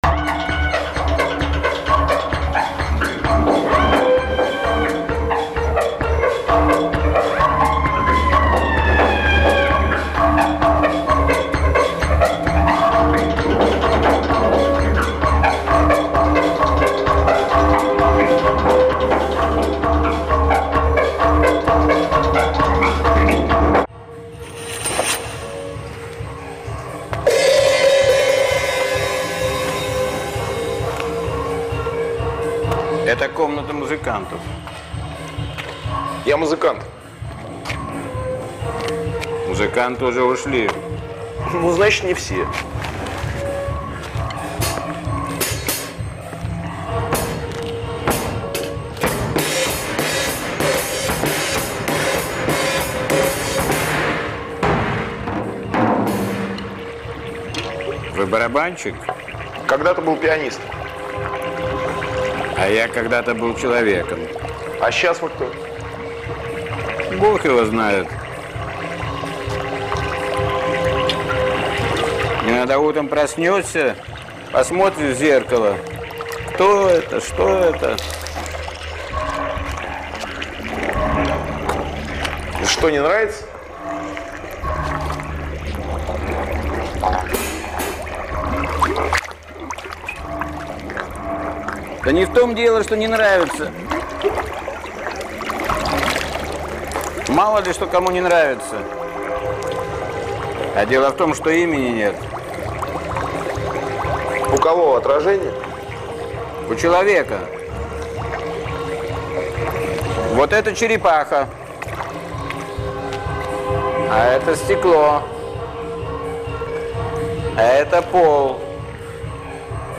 • Жанр: Джаз-рок
hwost_i_s-dialog_hwosta_so_shnurom_iz_filxma_4-2.mp3